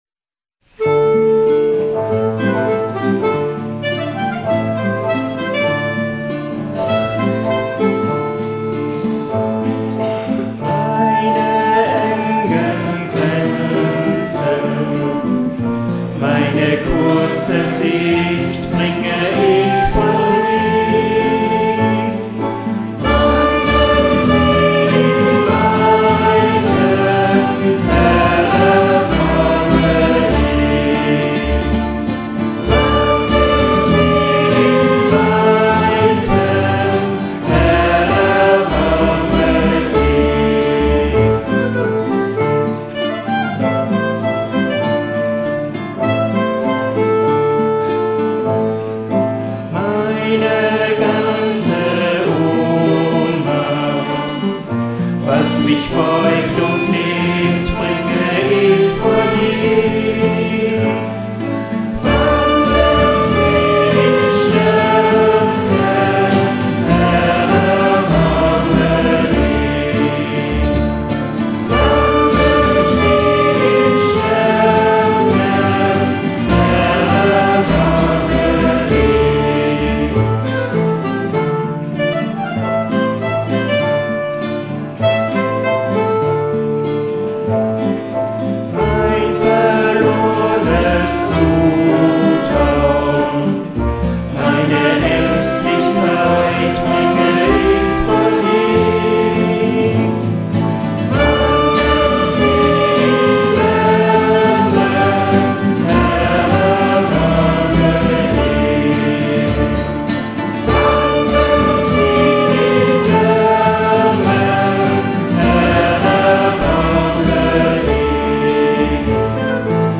Begleitet von der Spielgruppe
Den Regen an diesem Tag kann man zwar am Beginn und am Ende der Lieder hören.
Den kräftigen Volksgesang aber auch !